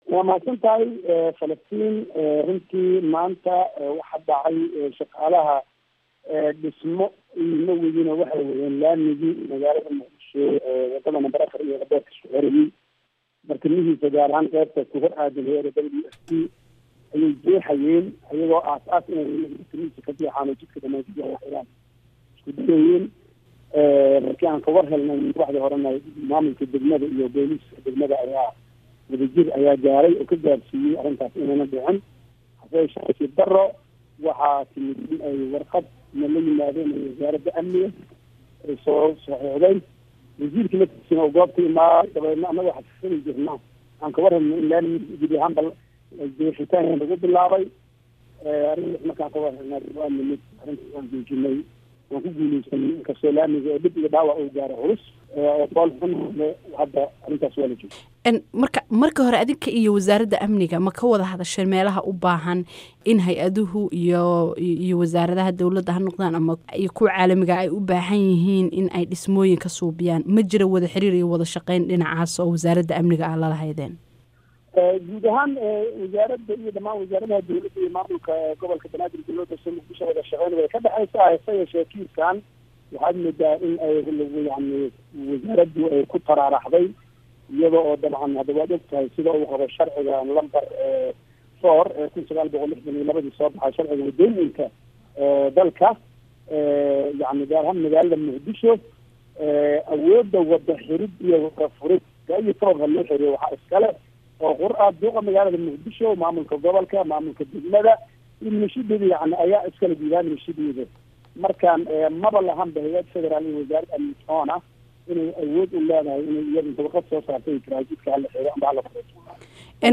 Wareysi: Muungaab